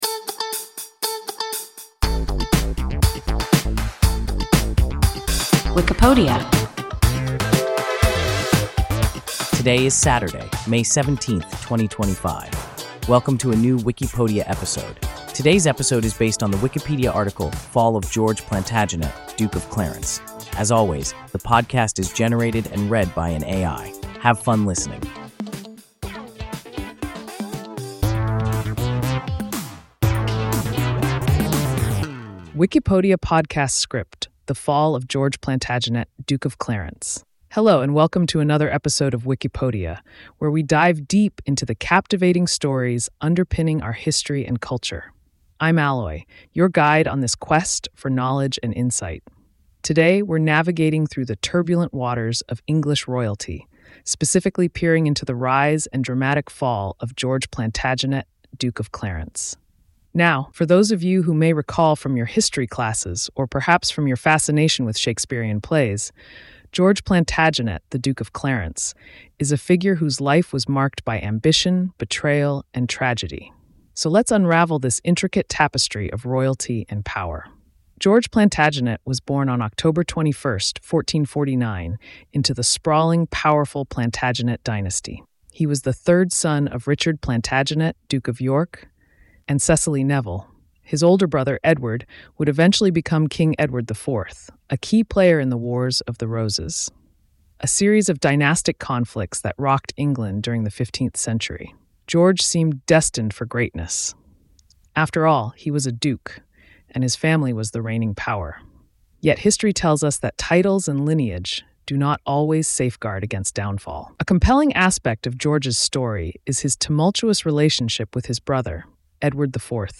Fall of George Plantagenet, Duke of Clarence – WIKIPODIA – ein KI Podcast